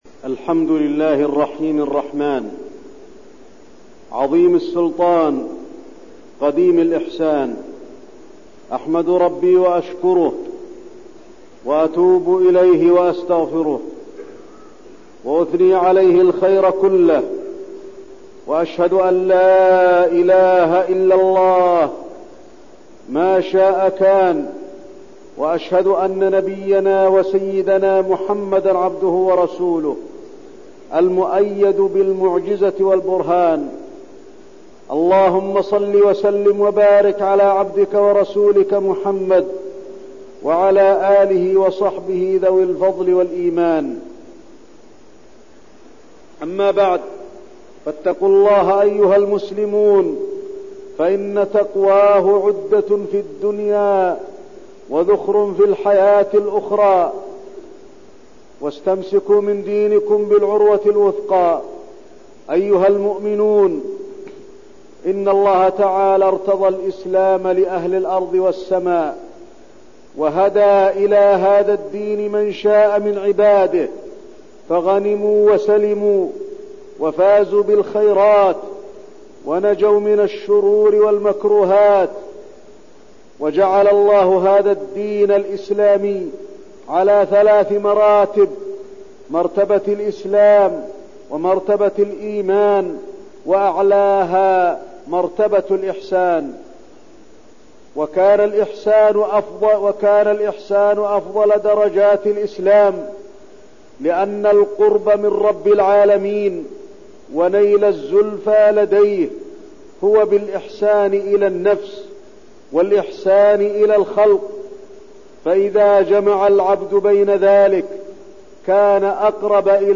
تاريخ النشر ١٤ شوال ١٤١٢ هـ المكان: المسجد النبوي الشيخ: فضيلة الشيخ د. علي بن عبدالرحمن الحذيفي فضيلة الشيخ د. علي بن عبدالرحمن الحذيفي الإحسان